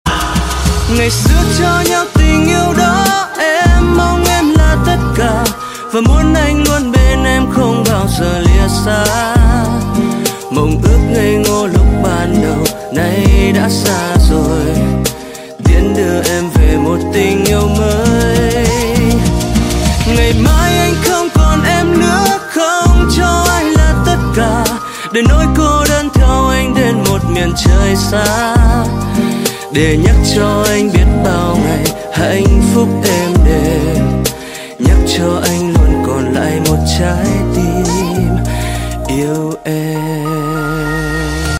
Thể loại nhạc chuông: Nhạc trẻ HOT